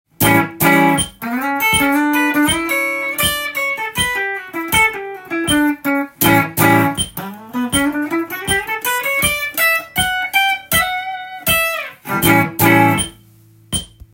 A7だけでギターソロ
２小節毎にA7のコードを弾き　休符の部分でギターソロを弾きます。
A7でよく使われるスケールは、Aミクソリディアンスケールです
ミクソリディアンを弾くとおしゃれな感じが出るのでカッコいいですね！